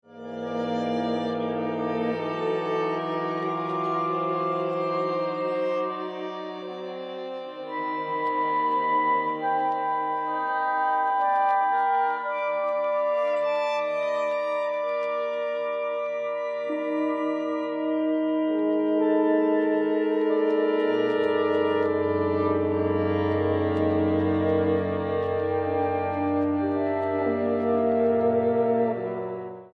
for music ensemble
Description:Classical; ensemble music
fl, ob, cl, bn, hn, vn(2), va, vc, db